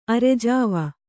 ♪ arejāva